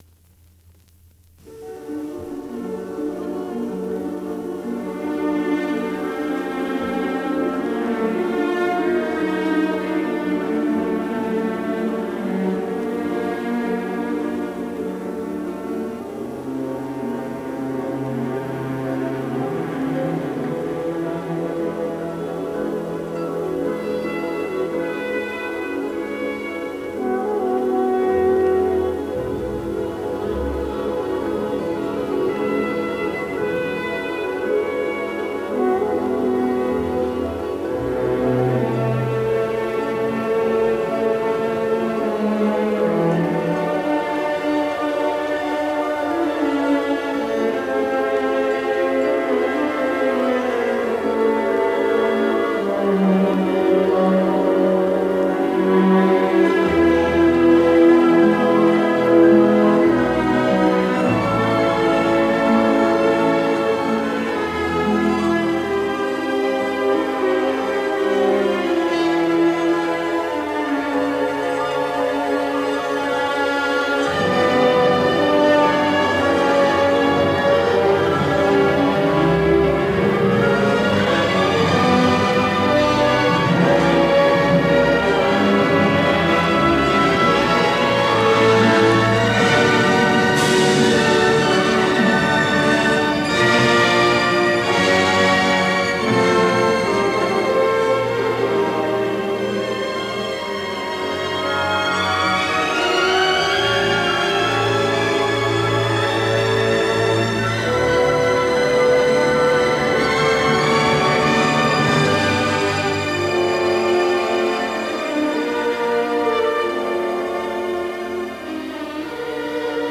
Per celebrare degnamente ho creato un file riunendo differenti interpretazioni dei primi minuti del Preludio di Die Gezeichneten, l’opera che appunto direttamente si svolge nel panorama fantastico della Riviera.
live, Frankfurt a. M., 1979
live, Salzburg, 1984